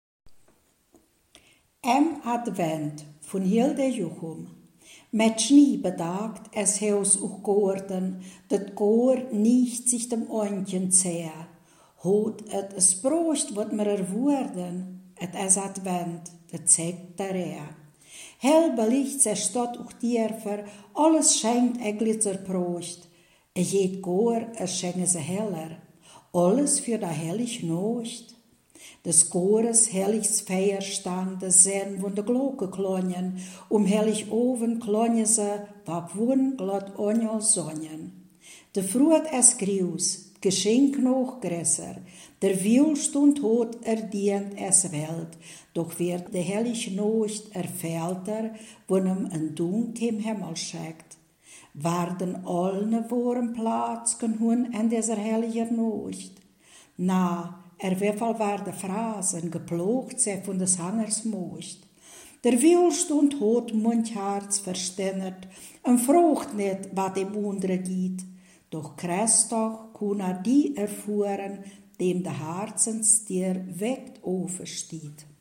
Ortsmundart: Frauendorf